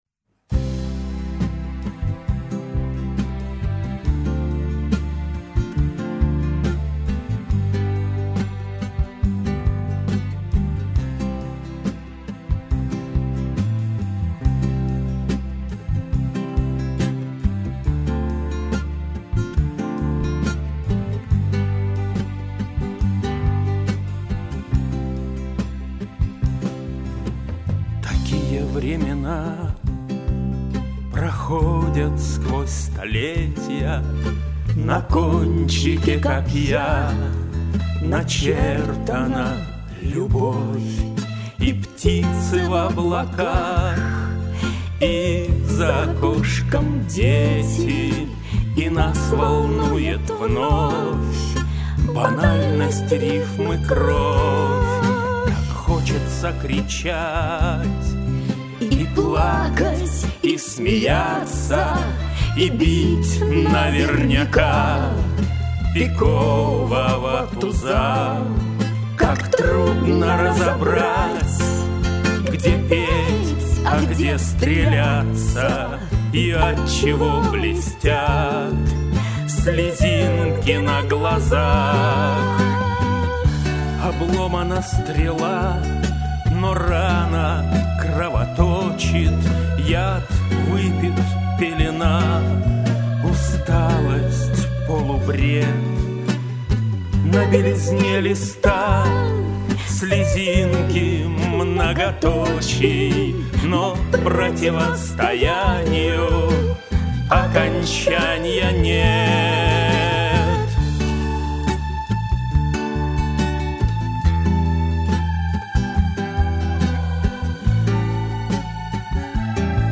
Исполняет автор
Вокализ